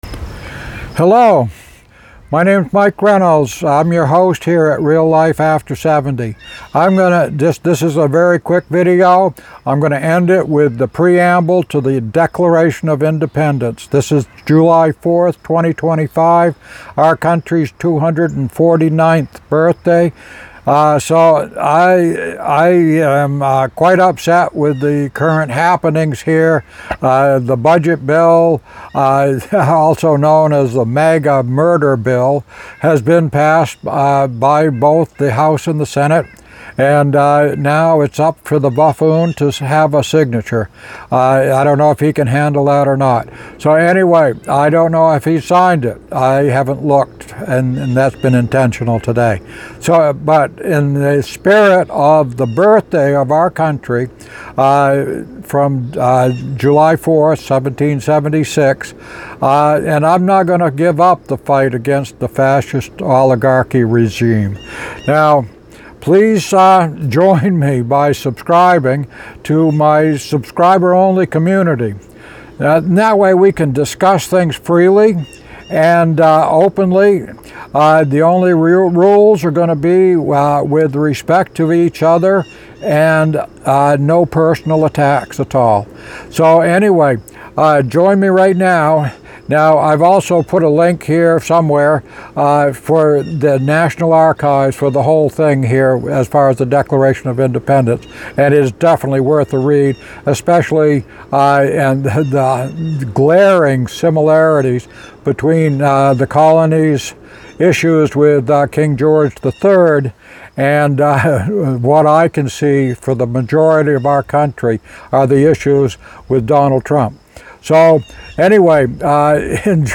So, as atribute to our country’s 249th birthday, here is a reading of thepreamble to our Declaration of Independence.